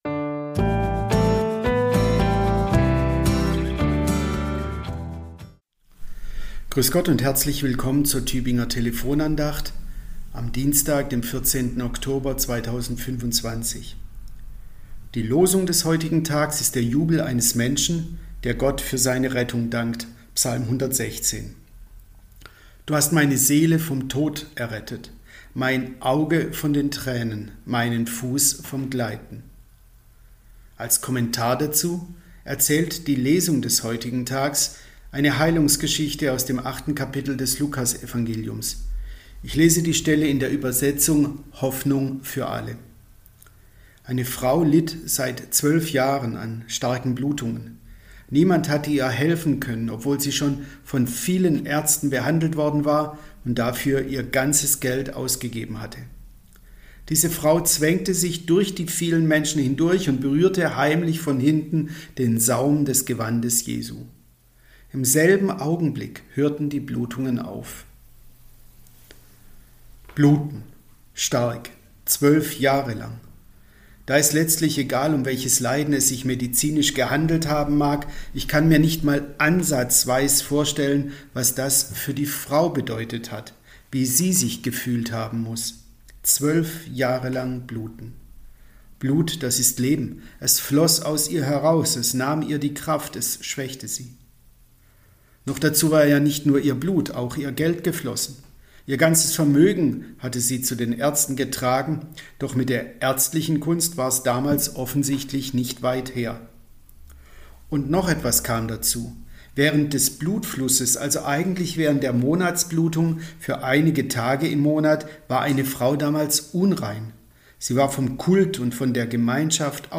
Andacht zum Wochenspruch